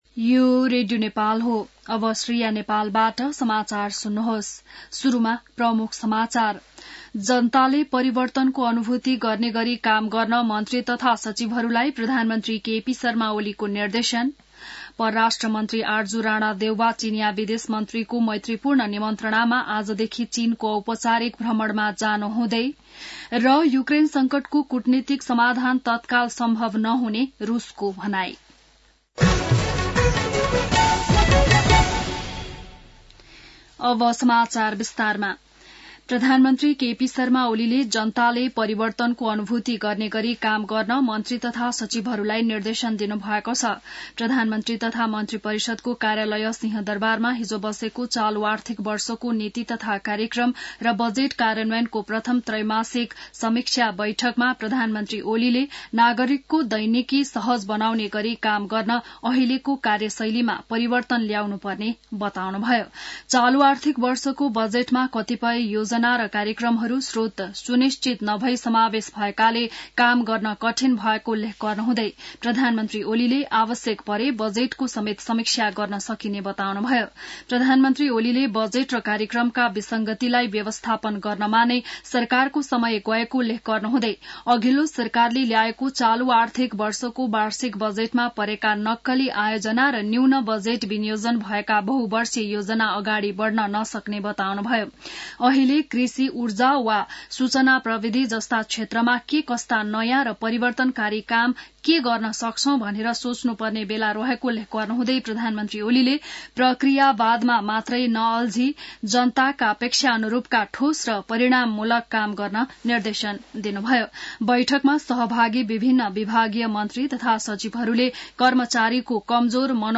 बिहान ९ बजेको नेपाली समाचार : १४ मंसिर , २०८१